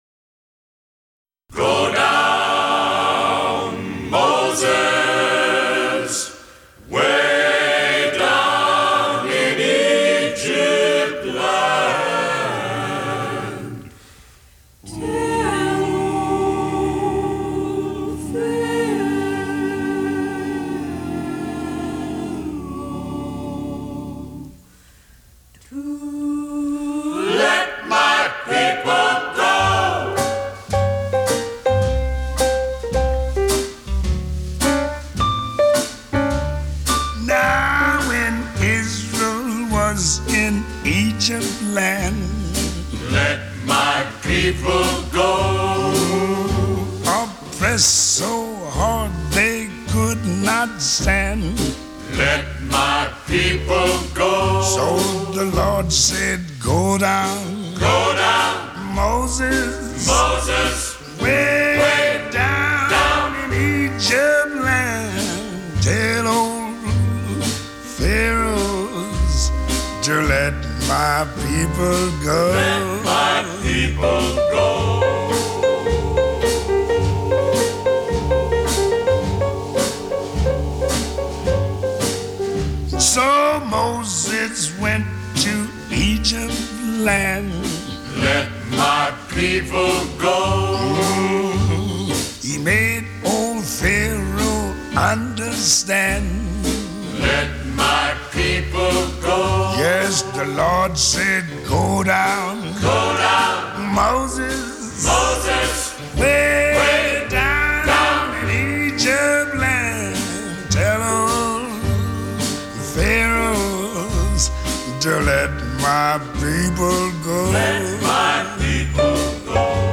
jazz
джаз